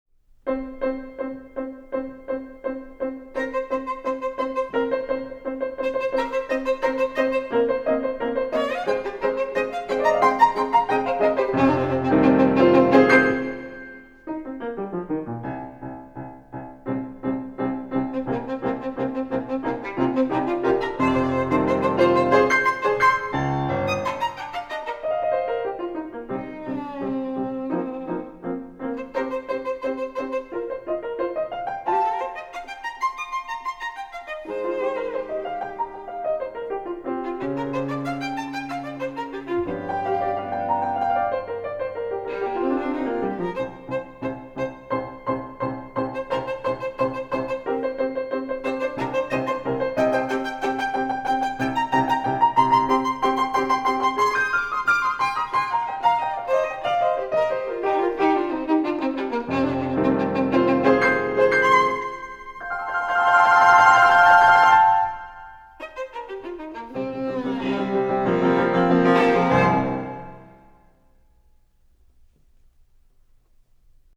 for Violin and Piano (1992)
violin
piano.